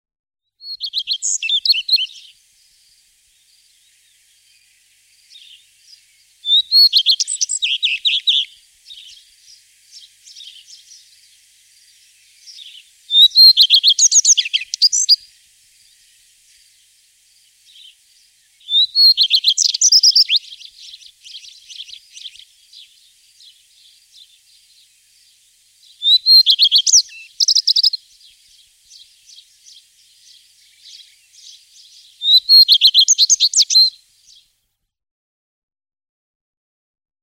Gartenrotschwanz
Klicken Sie auf das Bild, um seine Stimme zu hören.
gartenrotschwanz.mp3